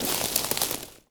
wood_tree_branch_move_16.wav